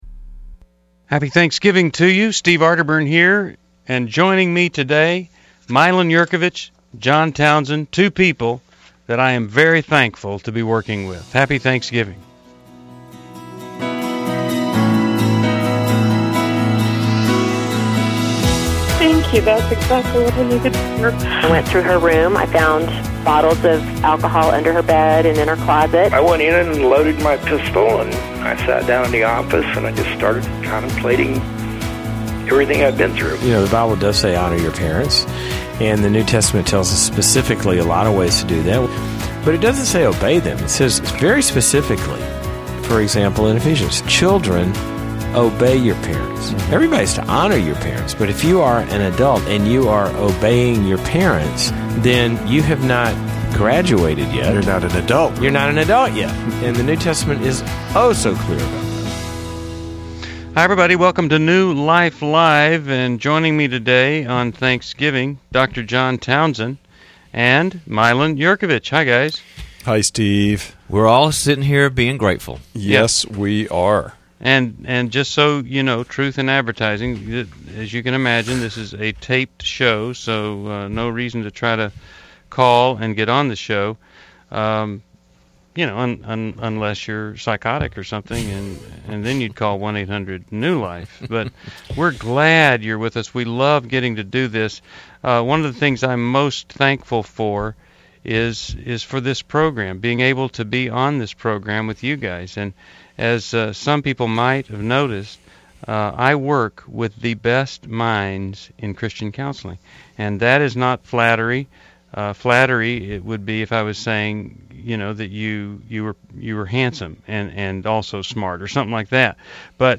Explore relationship struggles, parenting challenges, and isolation in New Life Live: November 24, 2011, as our hosts address real caller concerns.